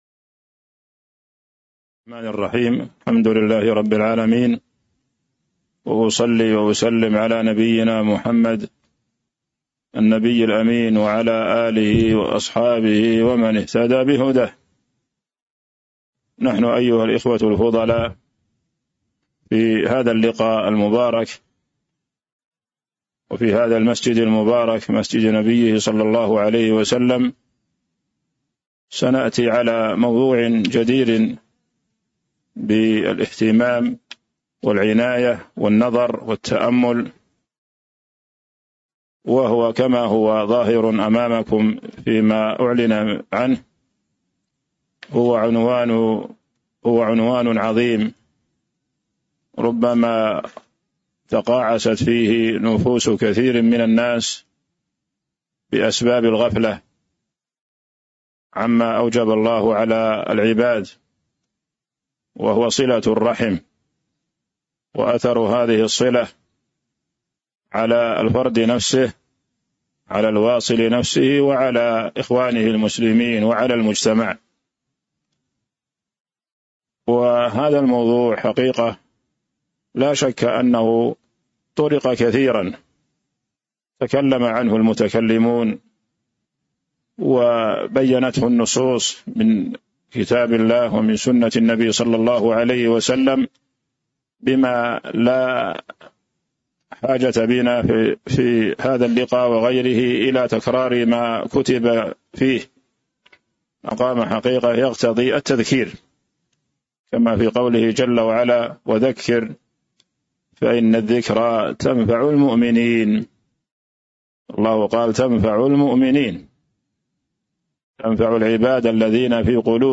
تاريخ النشر ١٥ محرم ١٤٤٥ هـ المكان: المسجد النبوي الشيخ